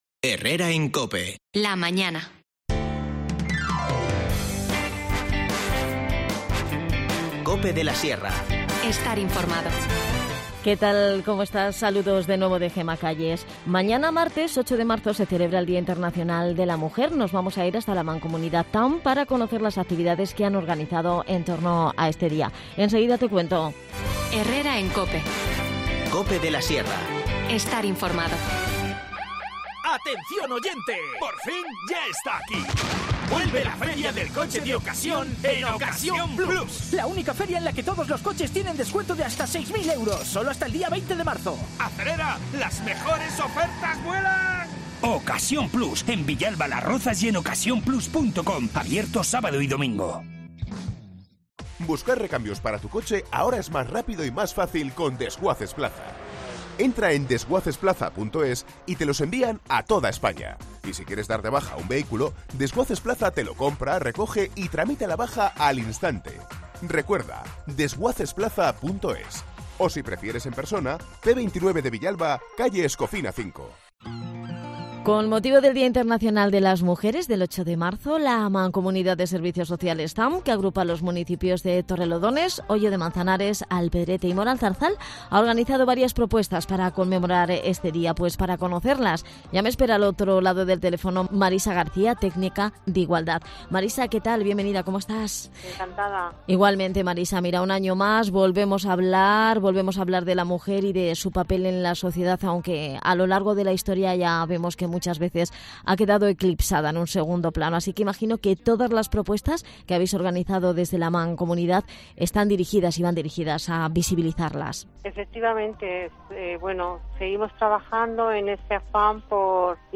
INFORMACIÓN LOCAL
Hablamos con